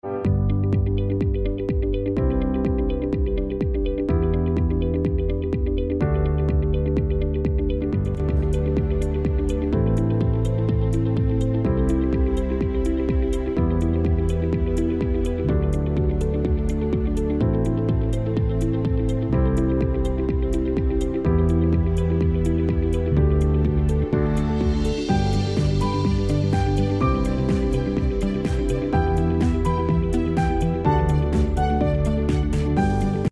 Souffleur électrique sans fil compatible